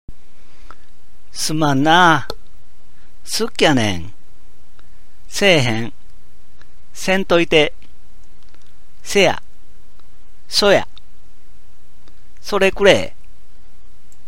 私は河内育ちの大阪弁を話しますが、聞いて分かりますか
引き続いて、大阪弁の独特の言葉をピックアップして、音声を録音しています。